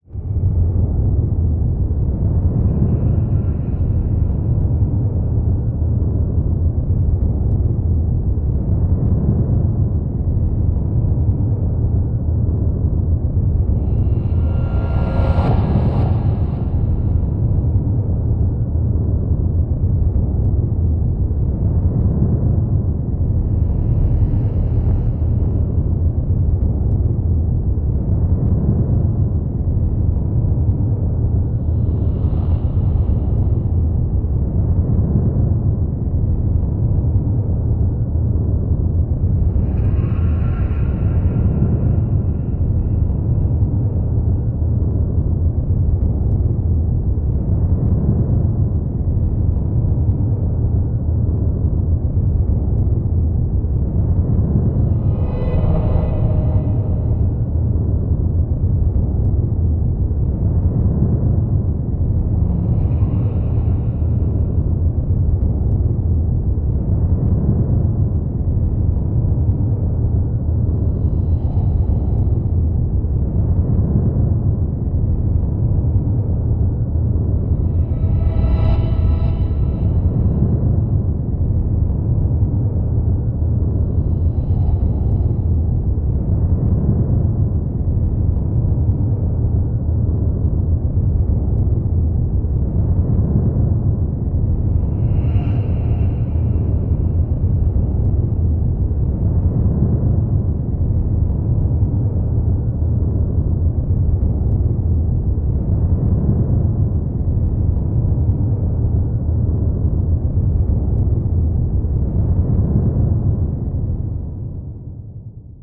free horror ambience 2
ha-pressure.wav